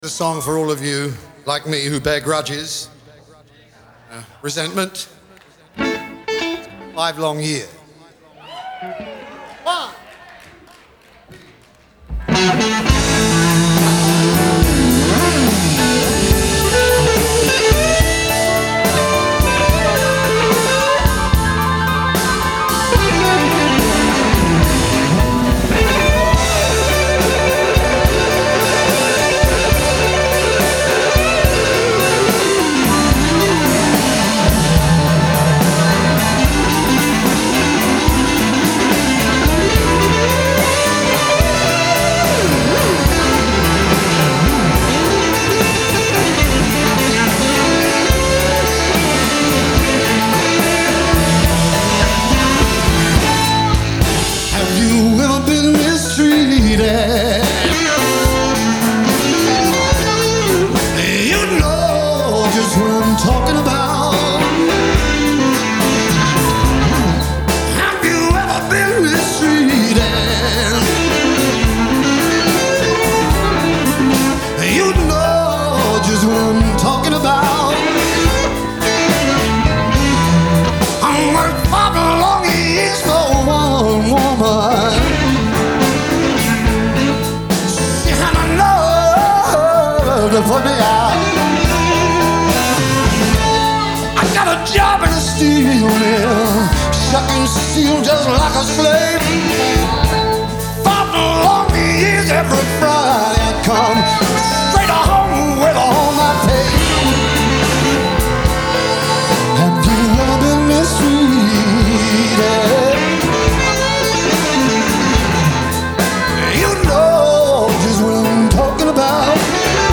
Genre : Blues ,Rock
Live at the Fillmore, San Francisco, 1994